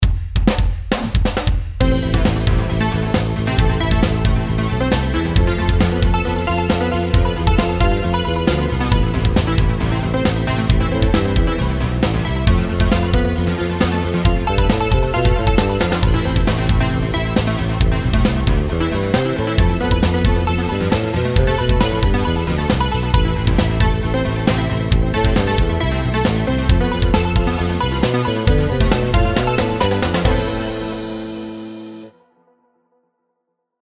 音楽ジャンル： ロック
楽曲の曲調： MIDIUM